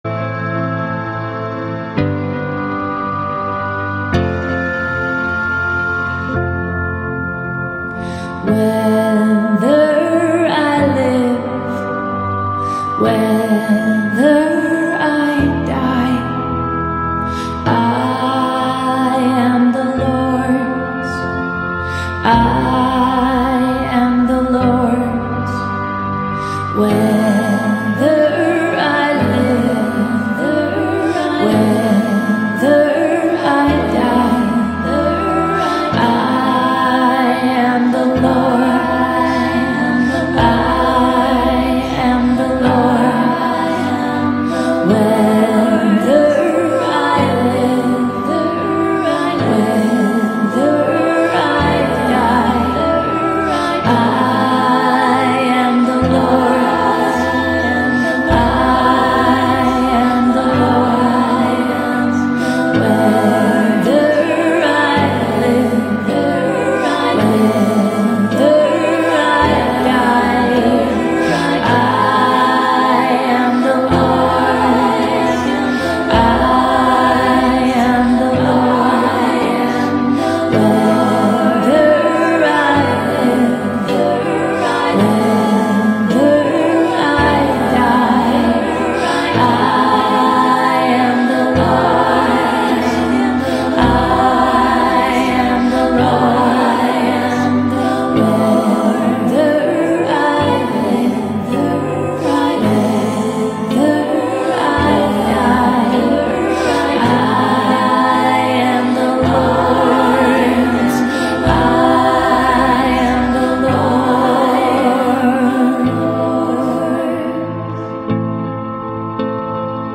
Chant: you the one, one in all, say I am, I am you – The Oriental Orthodox Order in the West